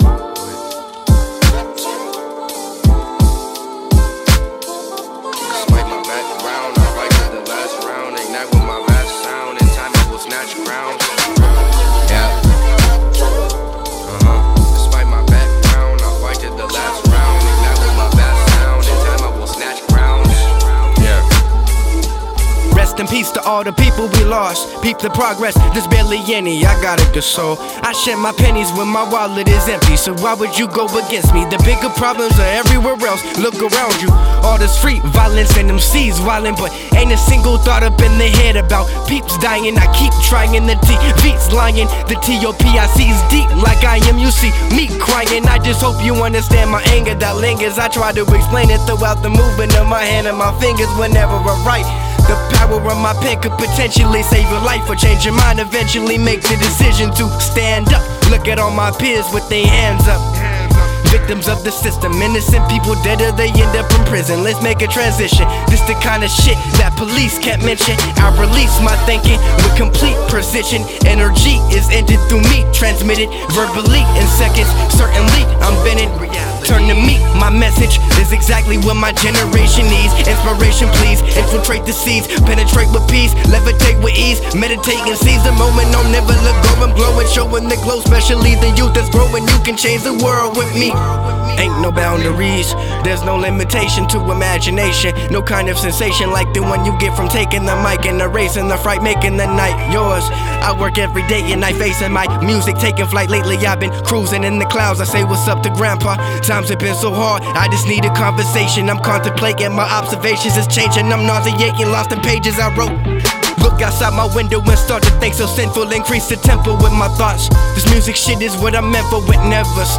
Recorded at AD1 Studios